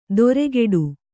♪ doregeḍu